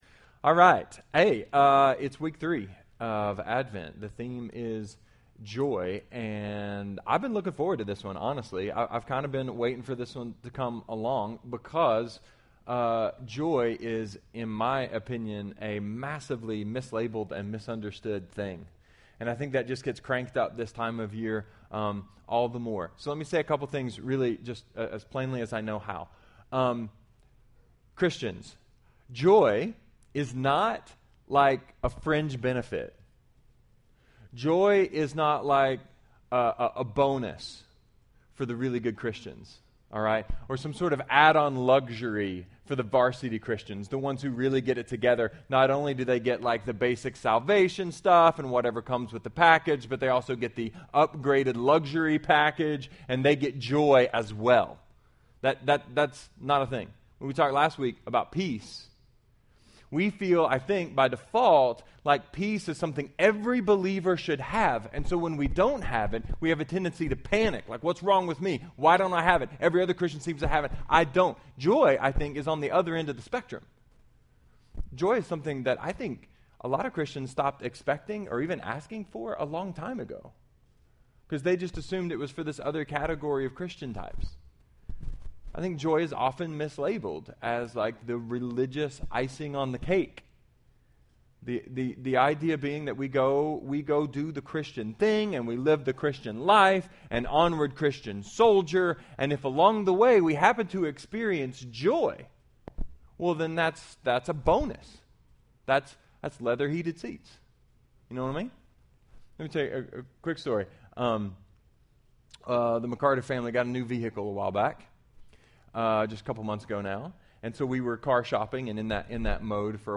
A sermon about joy, luxury, sudden death, and recognizing grace.